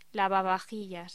Locución: Lavavajillas
voz